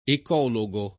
ecologo [ ek 0 lo g o ]